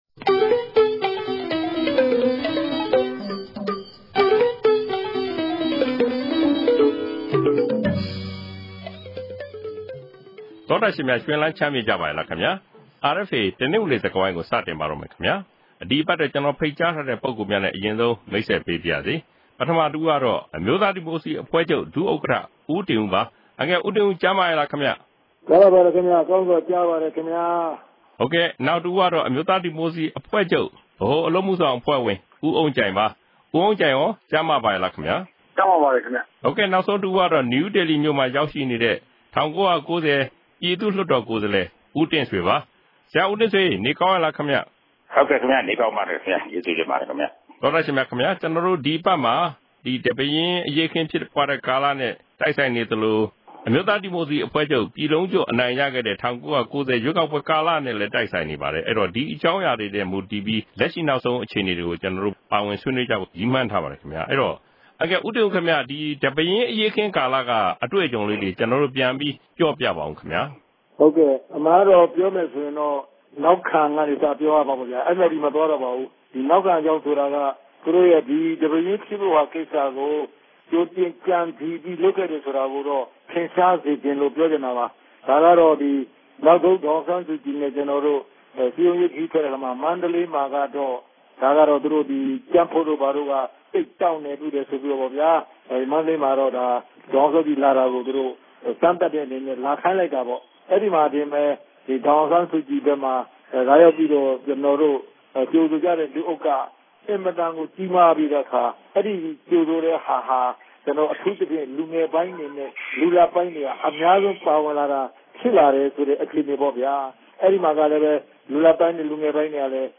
ဒီစကားဝိုင်းမှာ ပါဝင် ဆွေးနွေးထားသူတွေကတော့ အမျိုးသားဒီမိုကရေစီ အဖွဲ့ချုပ် ဒု-ဥက္ကဋ္ဌ ဦးတင်ဦး၊ ဗဟိုအလုပ်အမှုဆောင် အဖွဲ့ဝင် ဦးအုန်းကြိုင်နဲ့ အိန္ဒိယနိုင်ငံ နယူးဒေလီမြို့မှာ ရောက်ရှိနေတဲ့ ၁၉၉ဝ ပြည်သူ့လွှတ်တော် ကိုယ်စားလှယ် ဒေါက်တာတင့်ဆွေ တို့ ဖြစ်ပါတယ်။